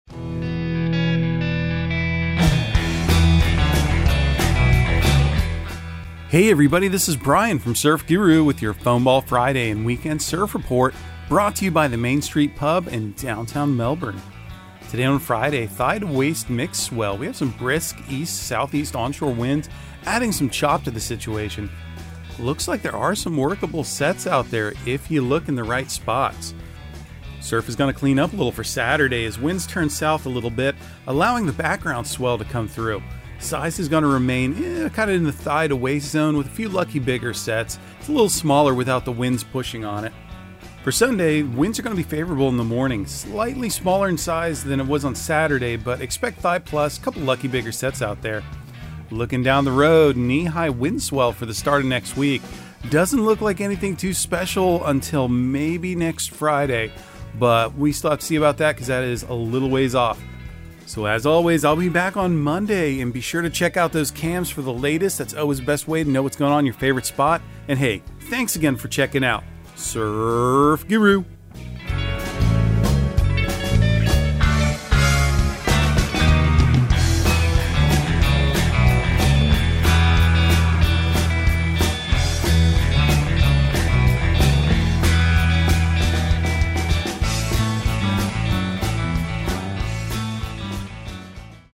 Surf Guru Surf Report and Forecast 03/31/2023 Audio surf report and surf forecast on March 31 for Central Florida and the Southeast.